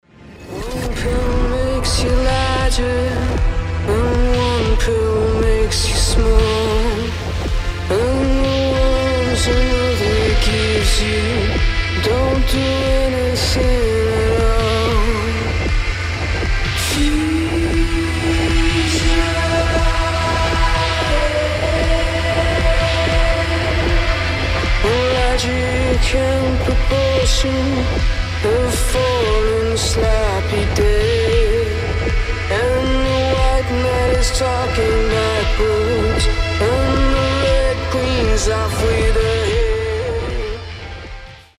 • Качество: 320, Stereo
атмосферные
indie rock